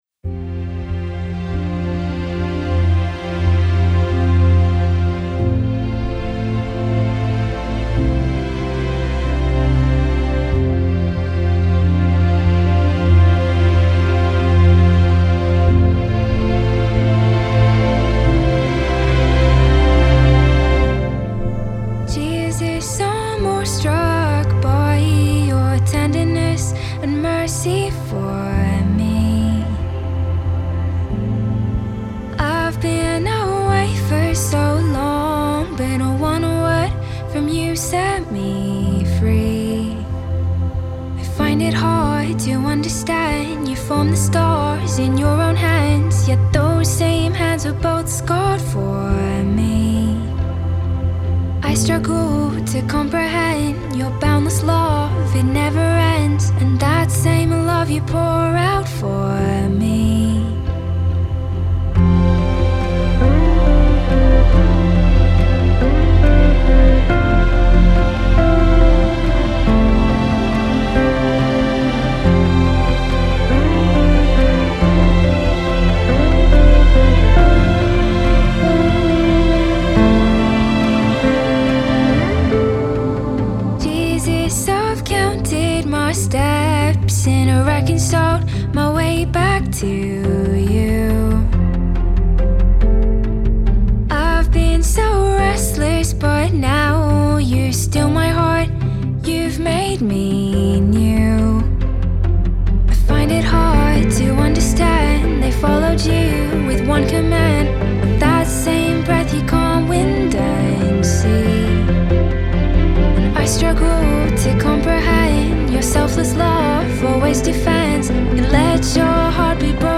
a beautiful, contemporary, worship ballad